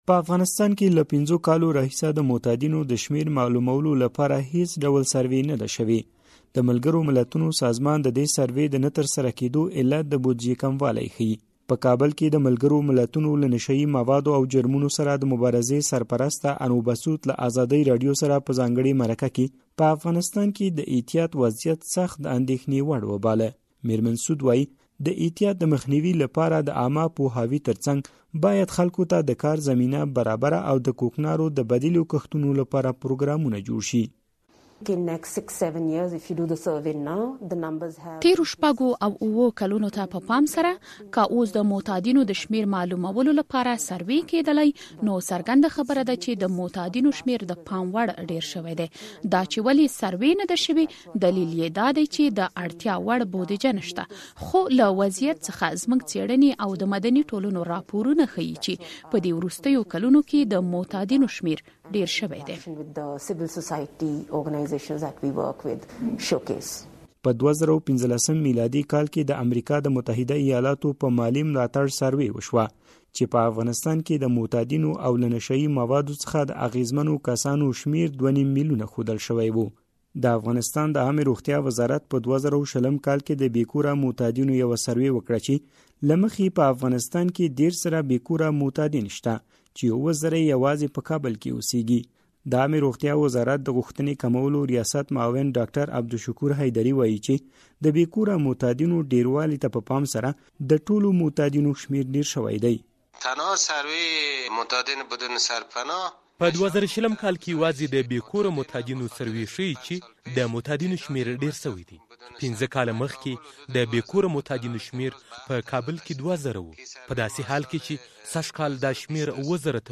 د زهرو کاروان-راپور